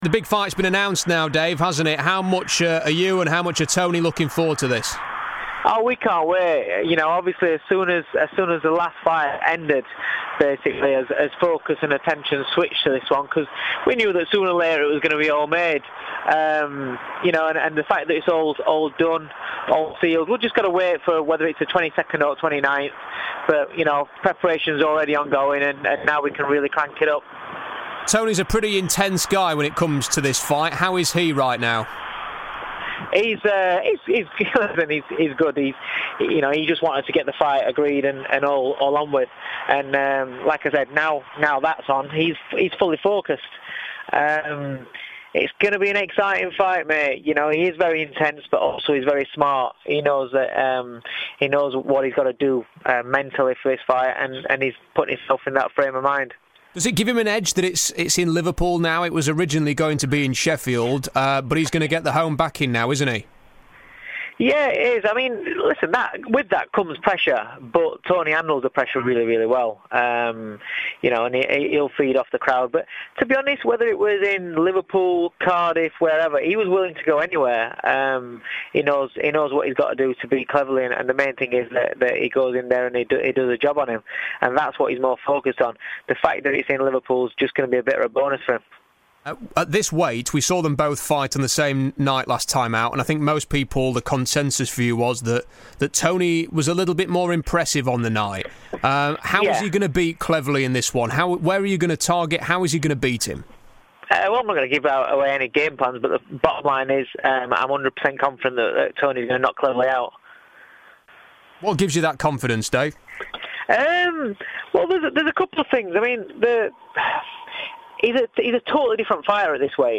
Football Heaven / INTERVIEW